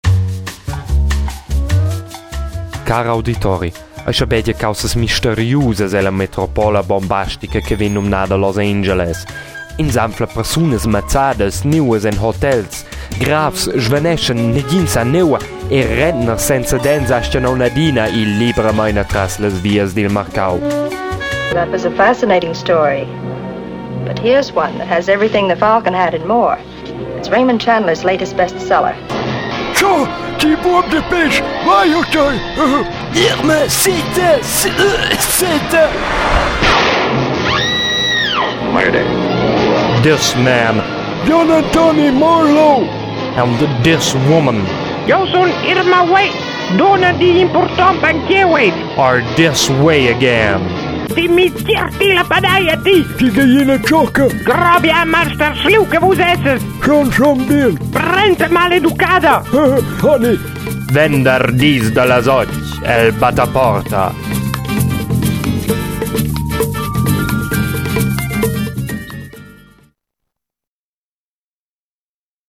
Dus giugs auditivs.